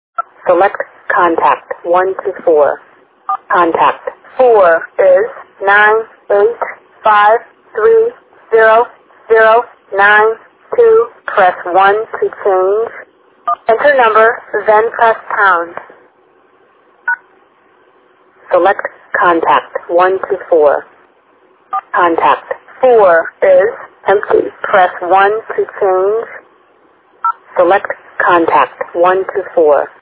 VM500-5 Voice Demonstration